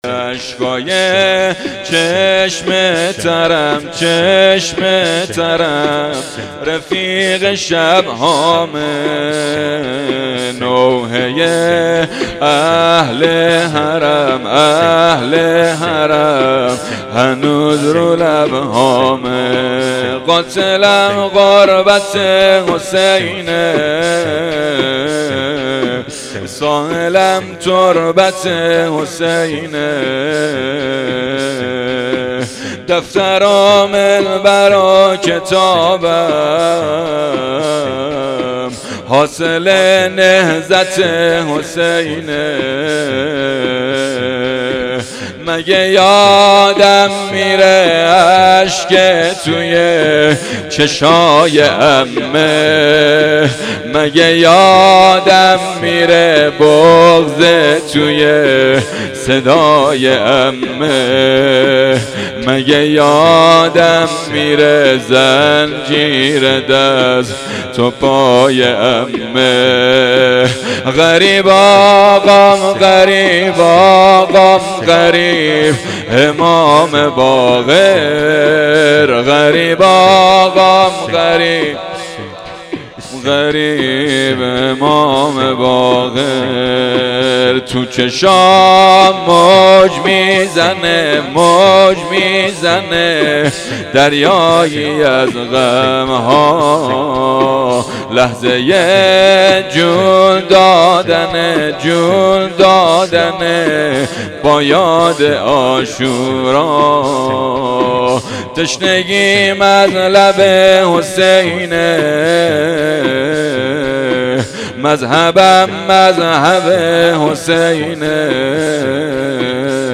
شور | اشکای چشم ترم رفیق شبهامه
شهادت امام باقر 1398